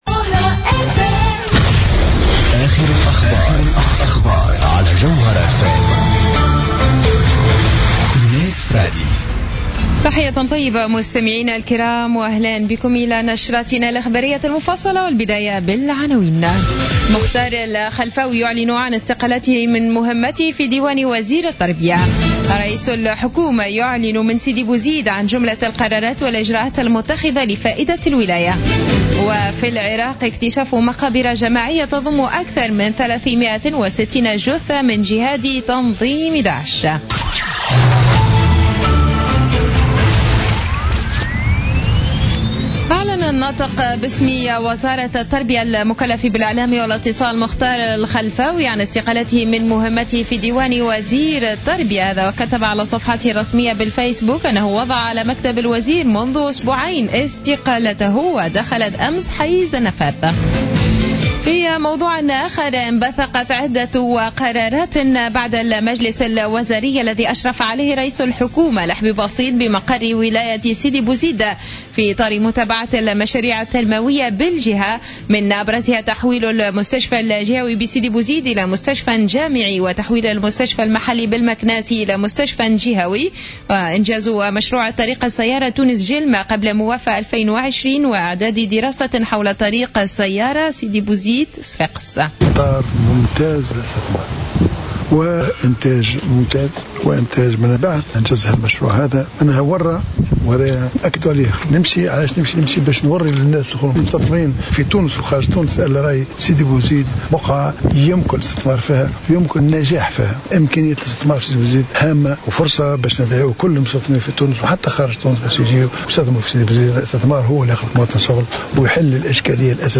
نشرة أخبار منتصف الليل ليوم الخميس 22 أكتوبر 2015